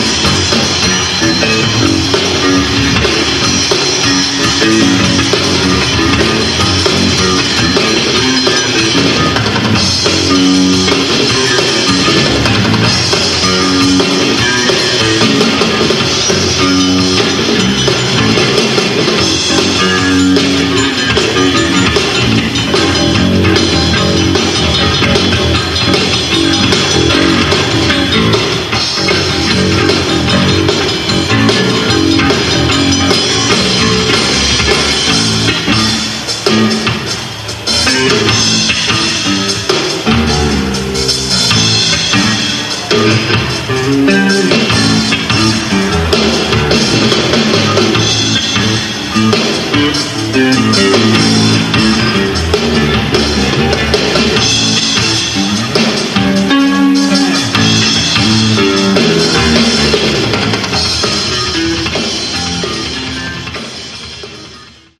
Category: Christian Melodic Metal